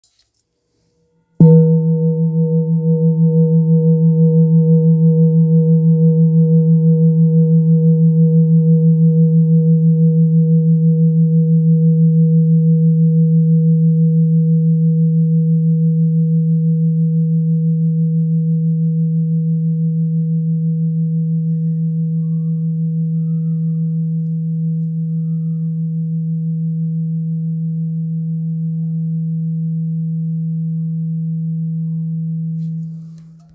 Kopre Singing Bowl, Buddhist Hand Beaten, Antique Finishing, 18 by 18 cm,
Material Seven Bronze Metal
It is accessible both in high tone and low tone .
In any case, it is likewise famous for enduring sounds.